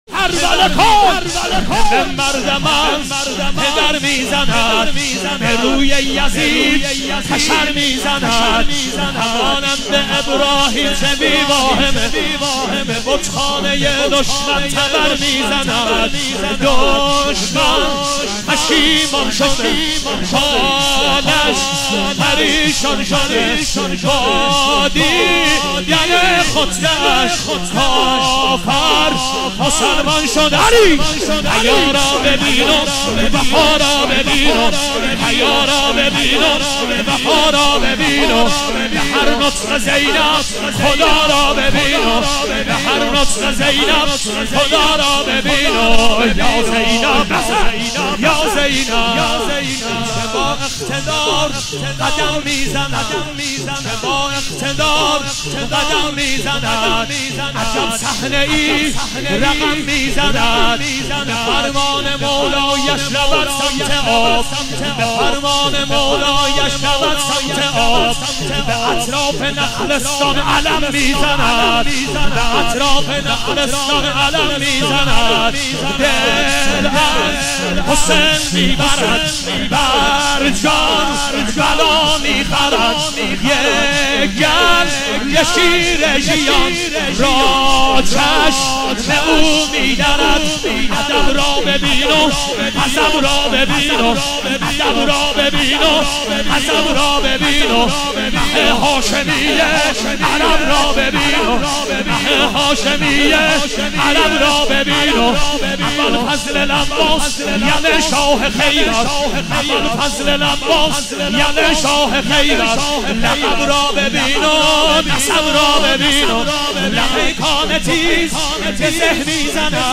مداحی
شهادت حضرت زینب (س) 98